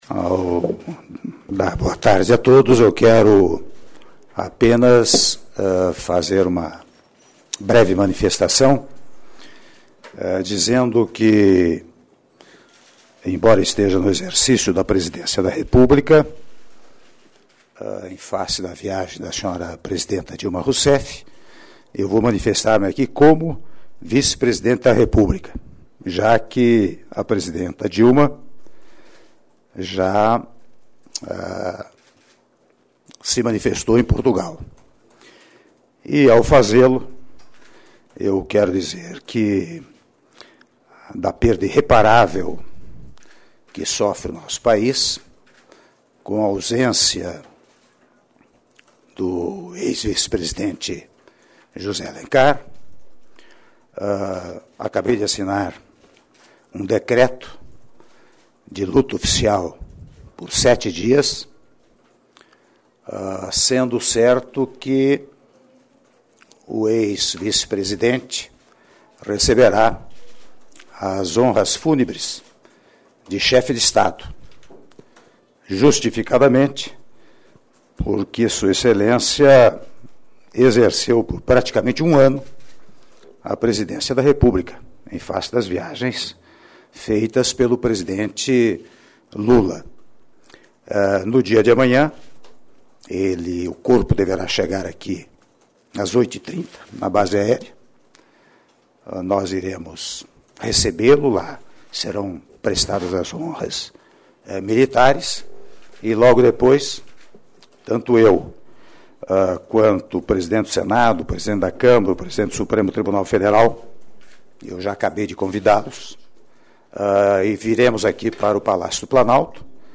Entrevista coletiva concedida pelo Presidente da República em exercício, Michel Temer, após o anúncio do falecimento do ex-vice-presidente José Alencar - Brasília/DF
Palácio do Planalto, 29 de março de 2011